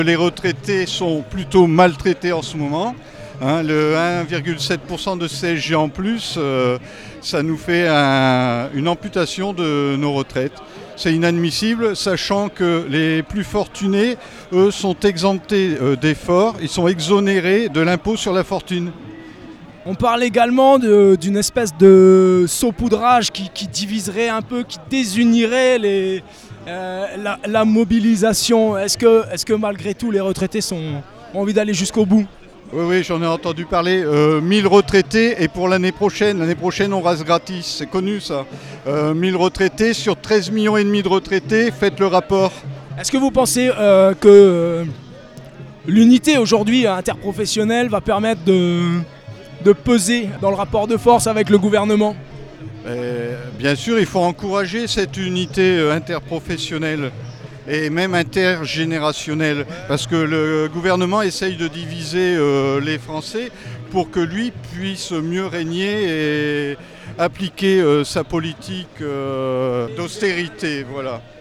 Une journée de mobilisation inter-syndicale de la Fonction Publique a eu lieu le jeudi 22 mars 2018.
Pour écouter l’entretien avec un retraité, cliquer